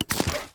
menu-edit-click.ogg